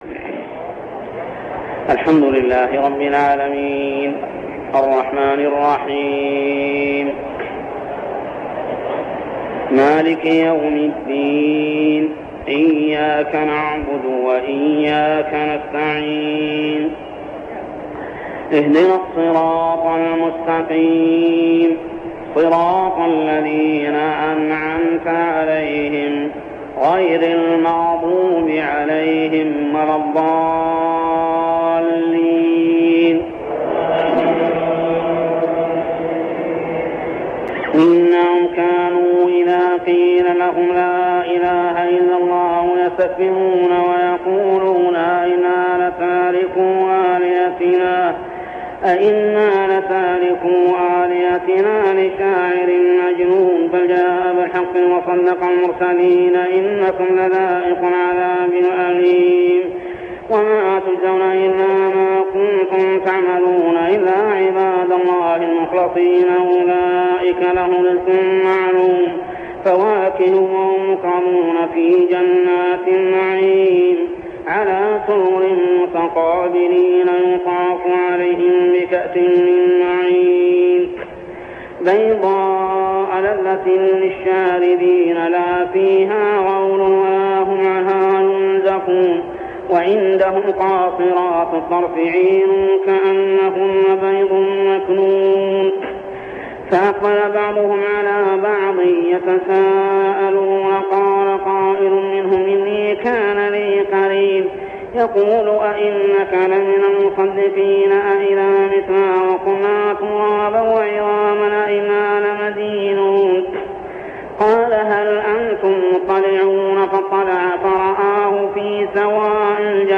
صلاة التراويح عام 1403هـ سورتي الصافات 35-182 و صٓ 1-64 ( الآيات 170-175 مفقودة ) | Tarawih prayer Surah As-Saffat and Sad > تراويح الحرم المكي عام 1403 🕋 > التراويح - تلاوات الحرمين